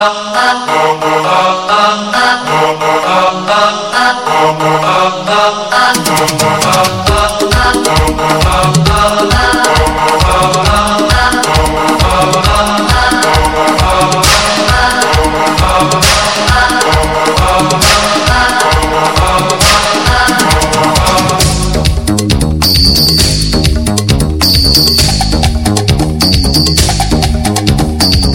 Kategorien Elektronische